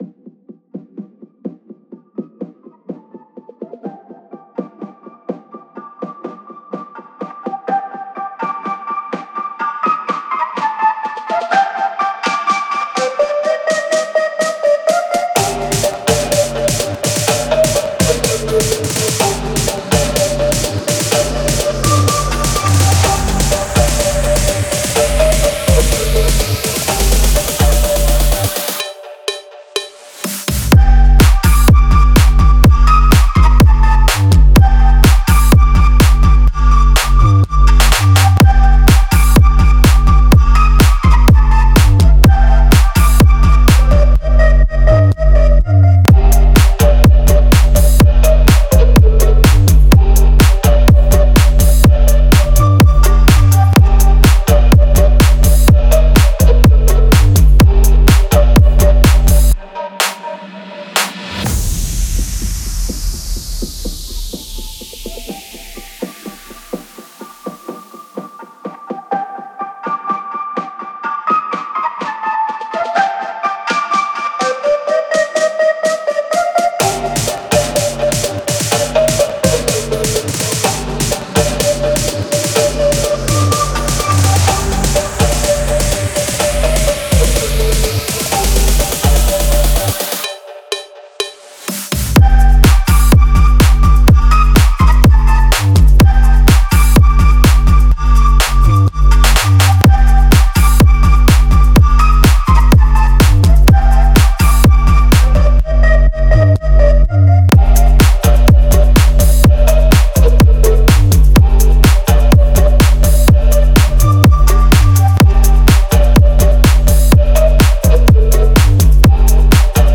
это завораживающий трек в жанре электронной музыки